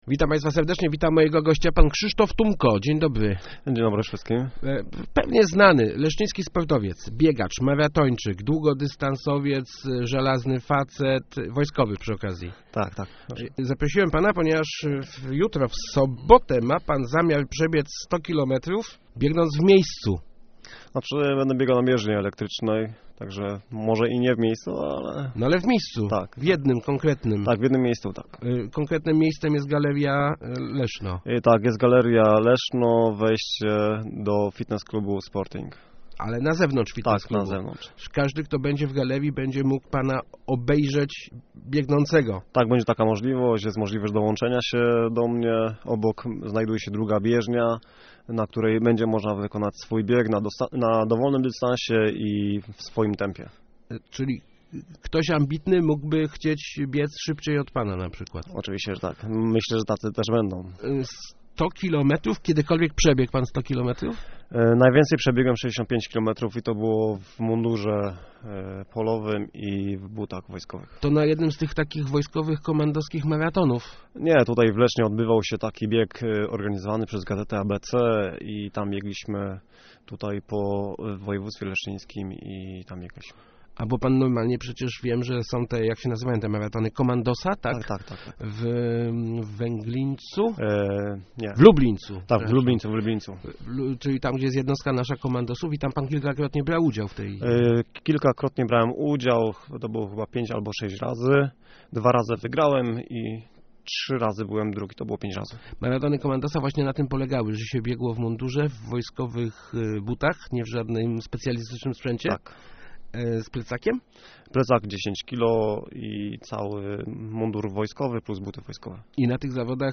Bardzo sympatyczny wywiad.